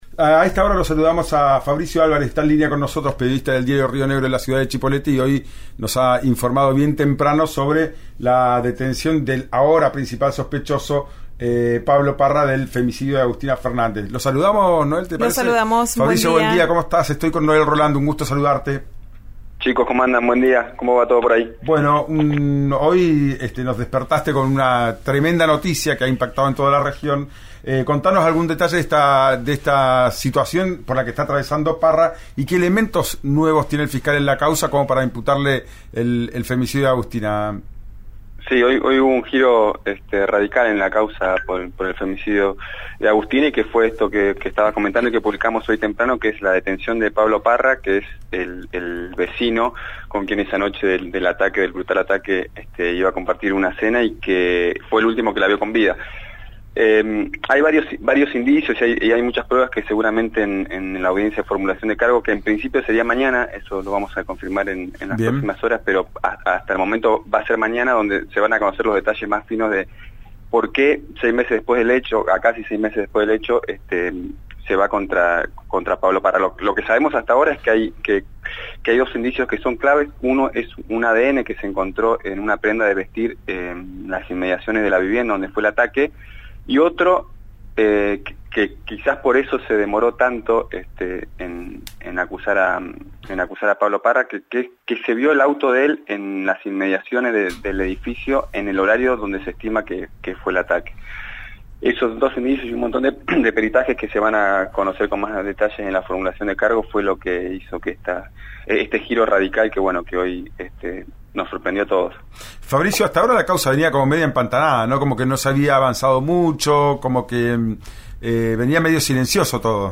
«Ya es tiempo» por RÍO NEGRO RADIO